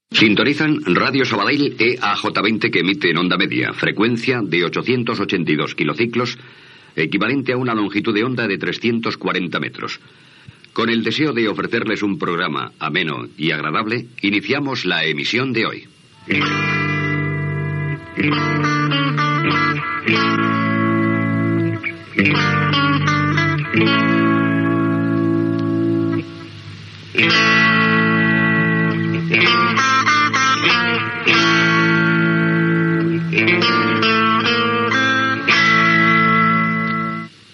Identificació de l'emissora, inici de l'emissió i sintonia